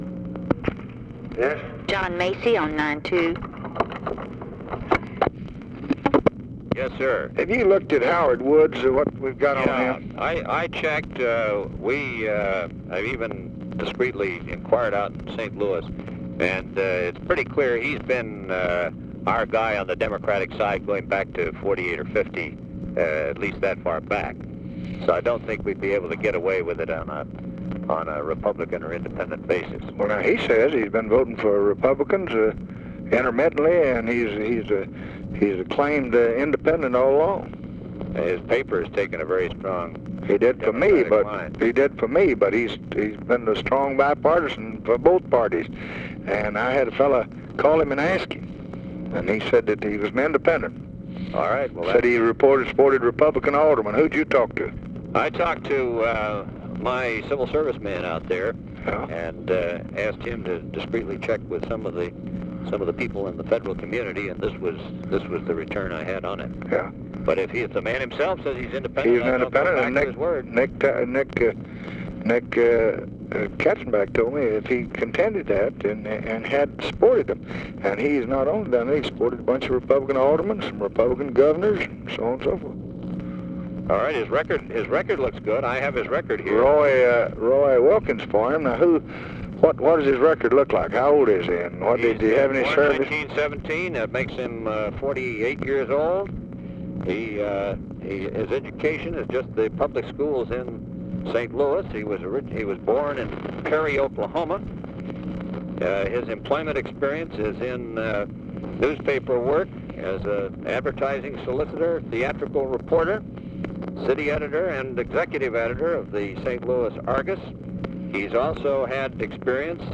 Conversation with JOHN MACY and HENRY FOWLER, March 18, 1965
Secret White House Tapes